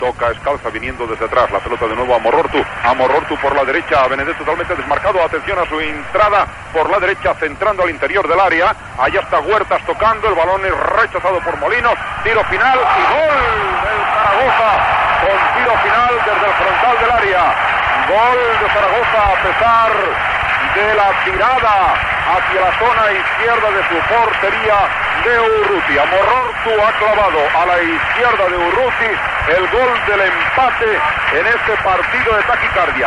Transmissió, des de Saragossa, del partit de la lliga masculina de la primera divisió de futbol entre el Zaragoza i el Real Club Deportivo Espanyol.
Esportiu